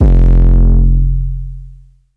UZ_bass_one_shot_808_boomin_F.wav